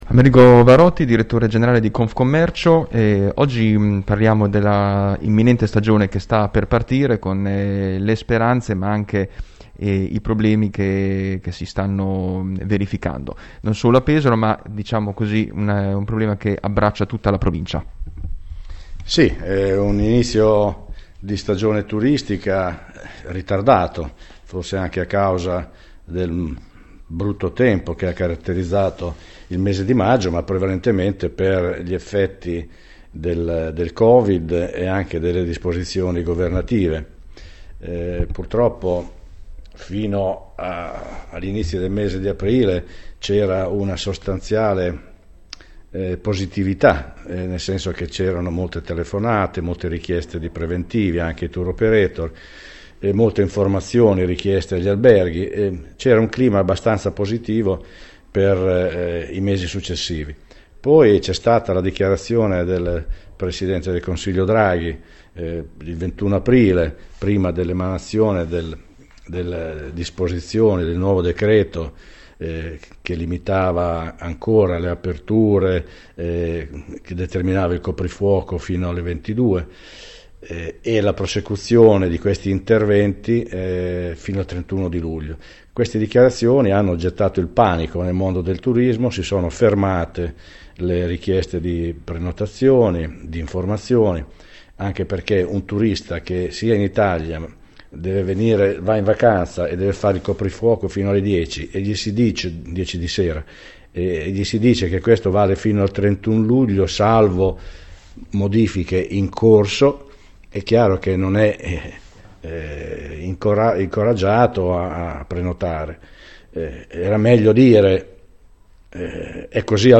Tanti gli argomenti affrontati in questa intervista